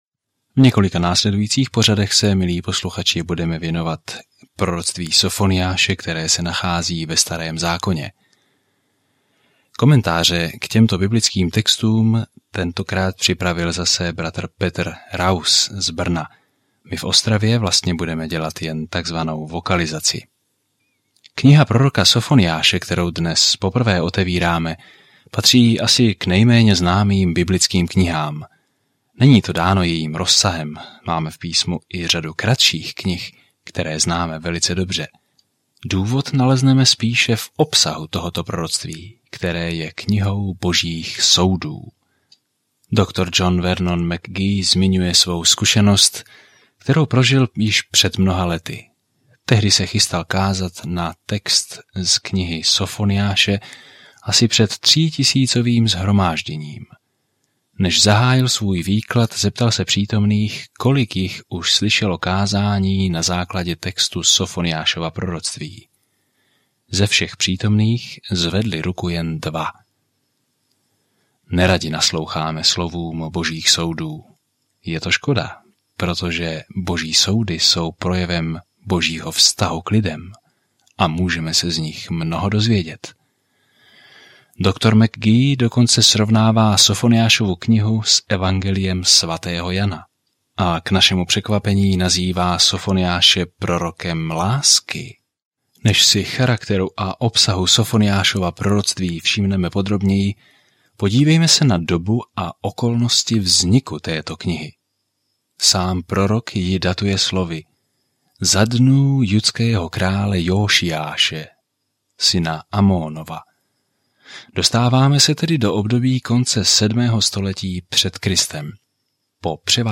Písmo Sofoniáš 1:1 Začít tento plán Den 2 O tomto plánu Sofoniáš varuje Izrael, že je Bůh bude soudit, ale také jim říká, jak moc je miluje a jak se nad nimi jednoho dne bude radovat zpěvem. Denně procházejte Sofoniášem, zatímco budete poslouchat audiostudii a číst vybrané verše z Božího slova.